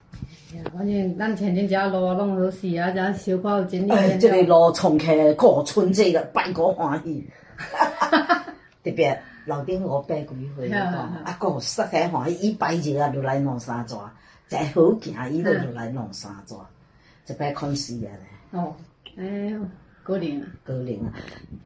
她指了指楼上，语气里带着几分高兴。